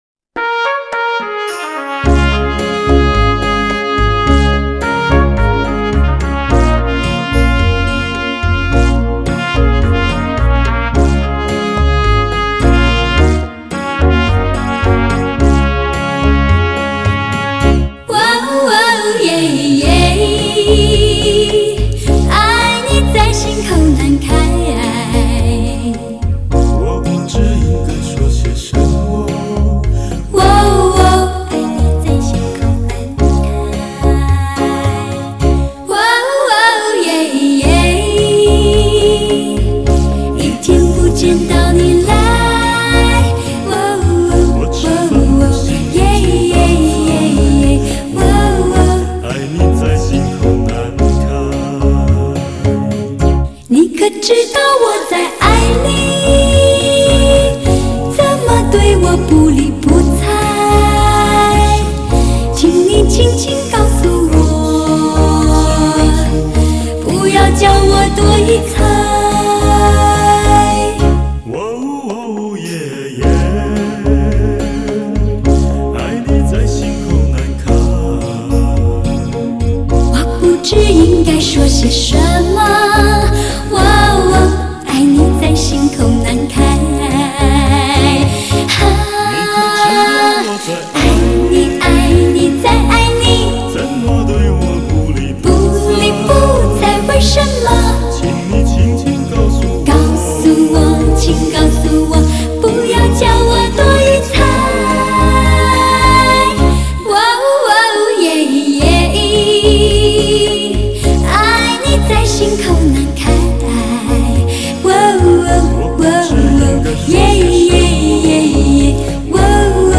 打造极品环绕声测试碟，多点定位人声试音典范，超乎想象顶级享受，高品质录制，典藏之首选。
午夜聽這男女團體對唱 音效果真是好哦
谢谢分享 极品环绕声测试碟，音效很好啊。